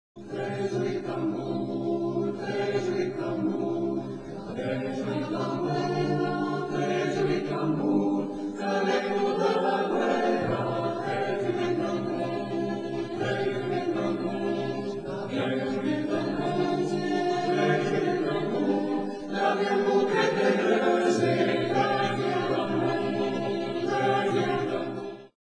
ASSOCIAZIONE CORALE CARIGNANESE APS